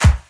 • Vintage Deep Pop Snare Sound C# Key 73.wav
Royality free steel snare drum sound tuned to the C# note. Loudest frequency: 1395Hz
vintage-deep-pop-snare-sound-c-sharp-key-73-AsJ.wav